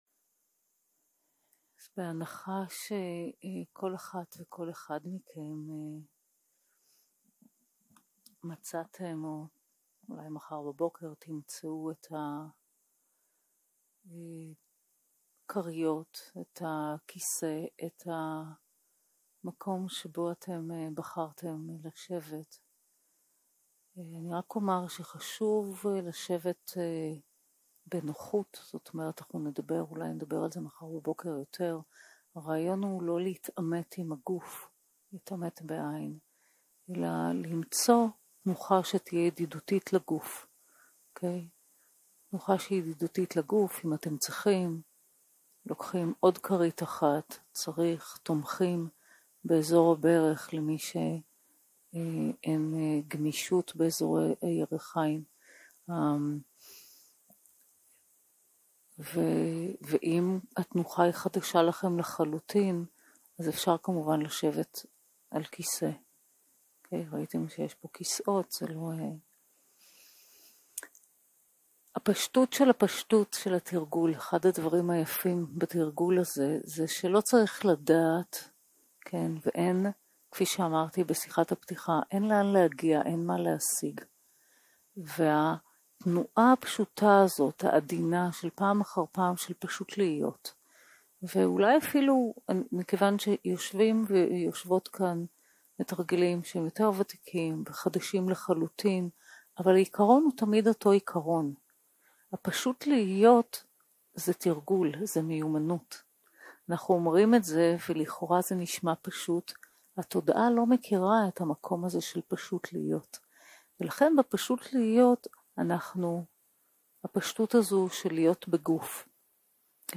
יום 1 - הקלטה 1 - ערב - מדיטציה מונחית - התבססות בגוף